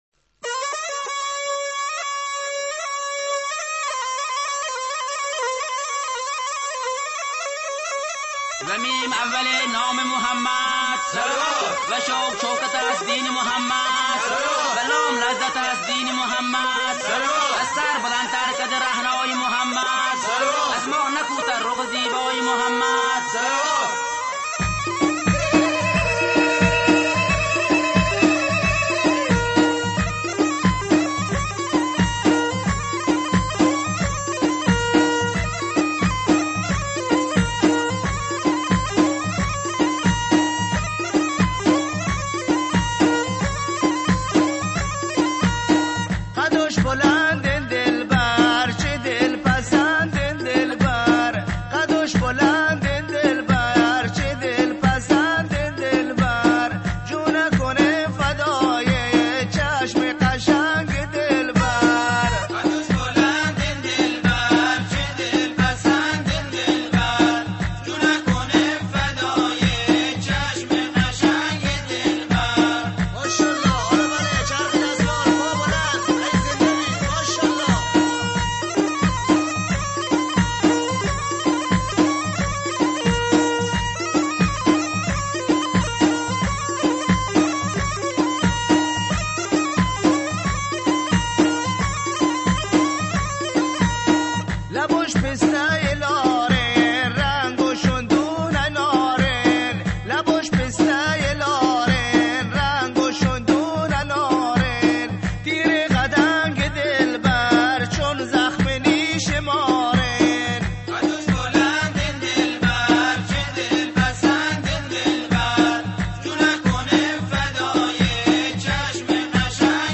اهنگ بستکی ۳۰ دقیقه ای قدیمی جون اکنم فدای چشم قشنگ دلبر رفتم خبری از یار بارم